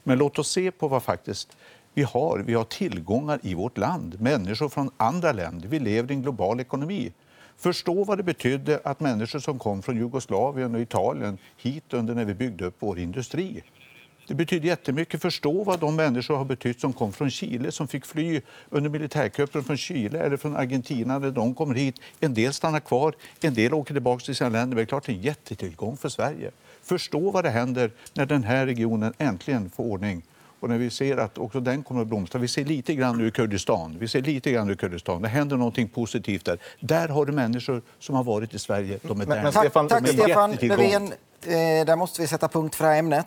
Under gårdagens partiledardebatt gjorde Stefan Löfven (S) ett tveksamt intryck i ämnet Asylrätten.